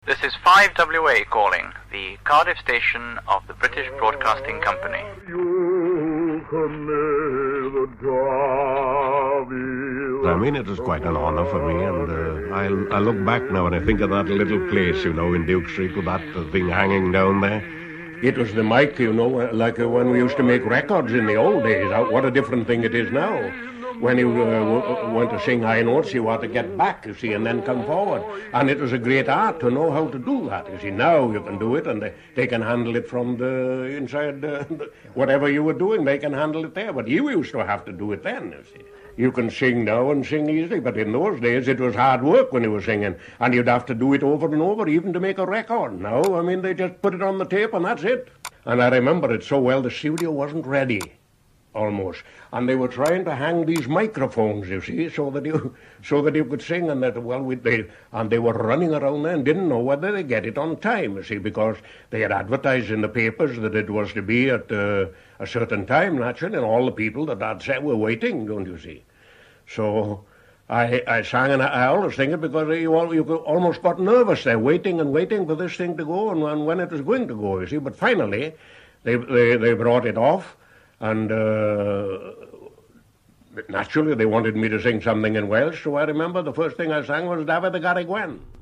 5WA launch story